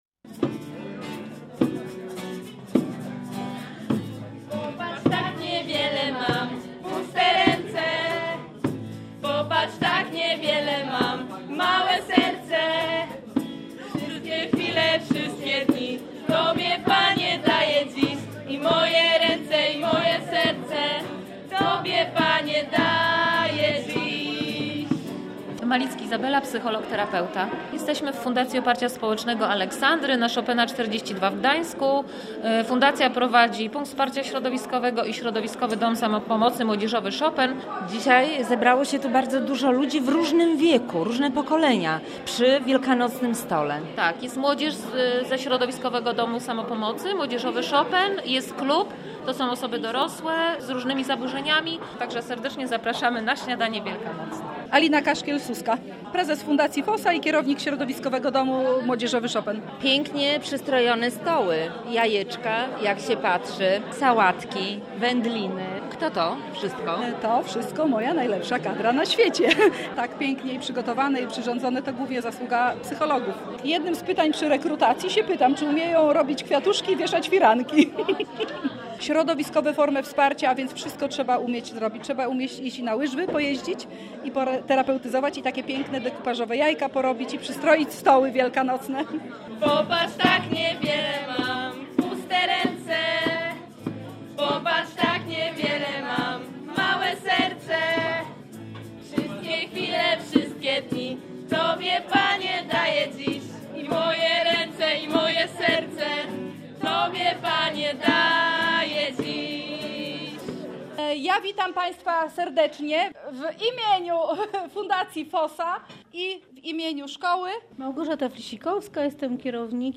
W przedświąteczny czas, przy pięknie przystrojonych i zastawionych stołach składają sobie życzenia, śpiewają i opowiadają o ważnych dla nich wydarzeniach.